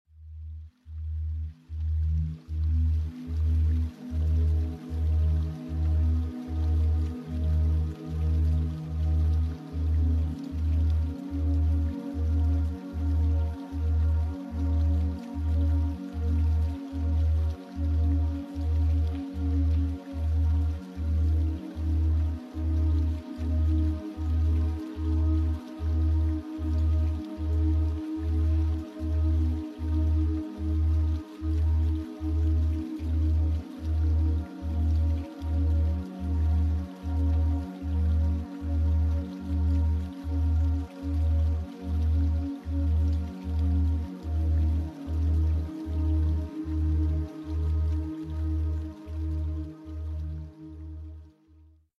8 expierence the calming effect of delta binaural beats